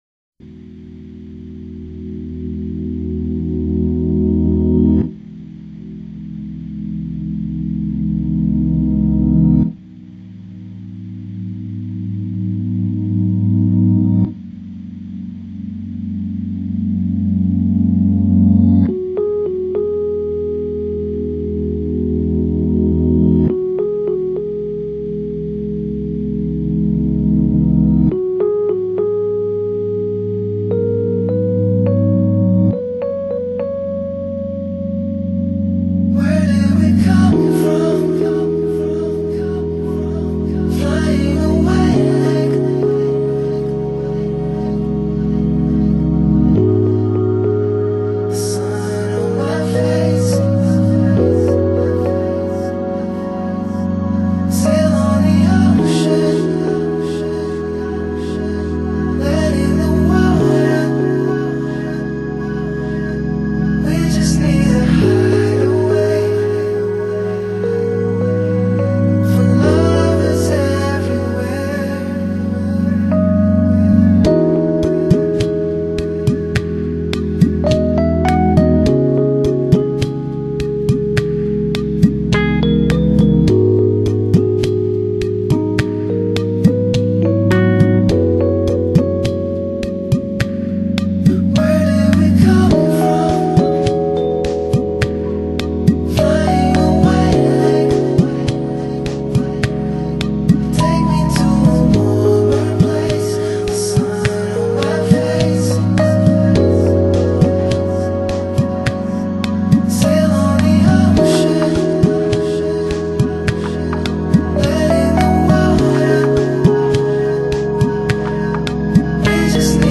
Genre: Lo-Fi, Chillout, Lounge